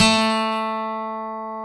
Index of /90_sSampleCDs/Roland L-CDX-01/GTR_Steel String/GTR_ 6 String
GTR 6-STR30W.wav